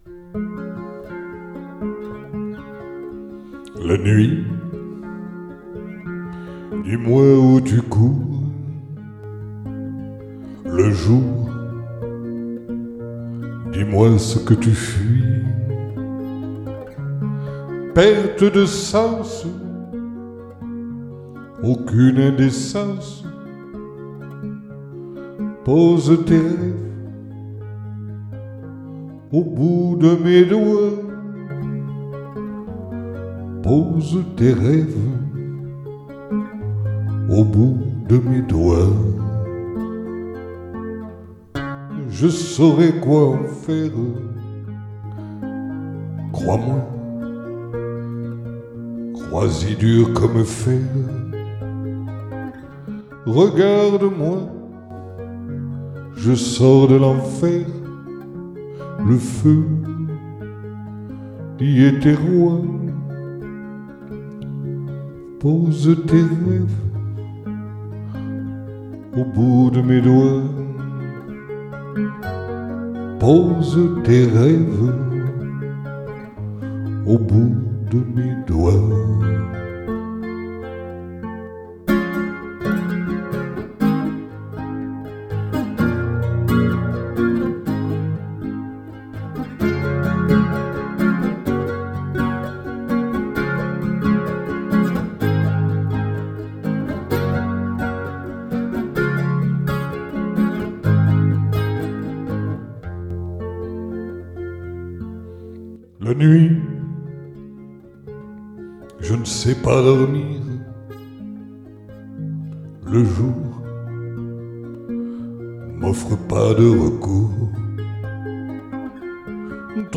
Picking
Pont musical frappé
Coda frappé